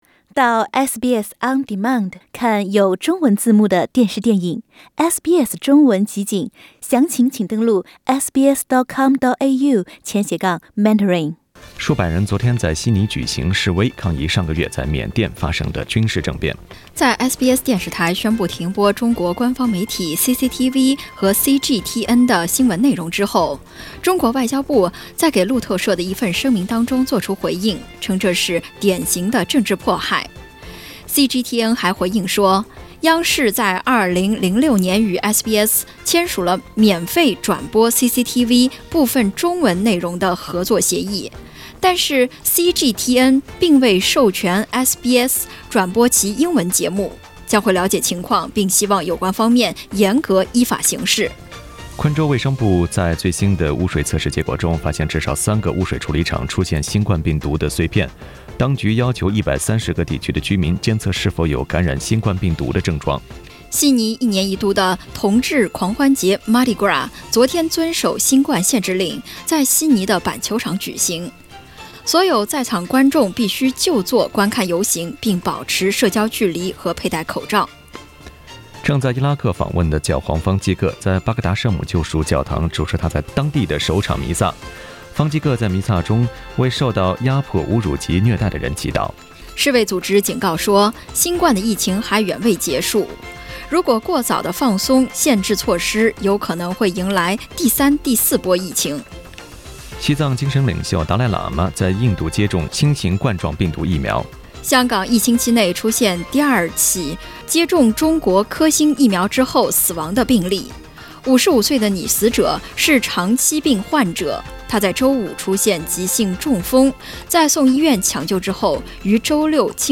SBS早新聞（3月7日）